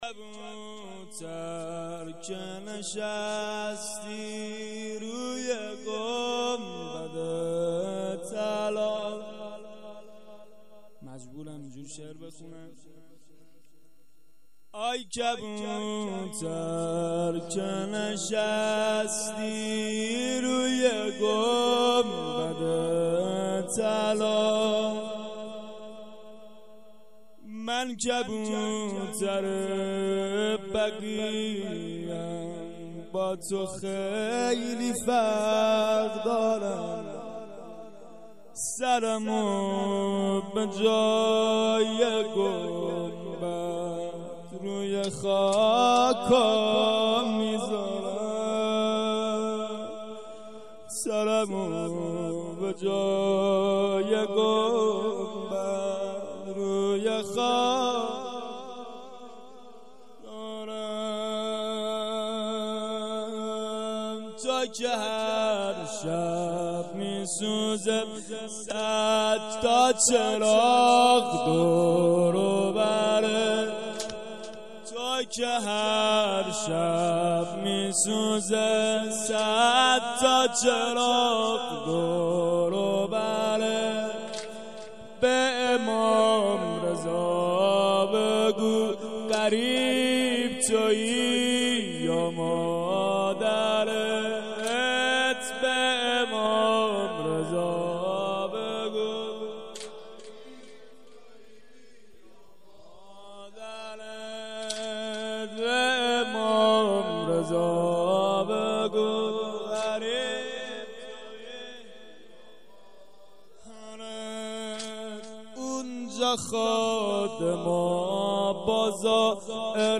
سنگین/ای کبوتر که نشستی به روی گنبد طلا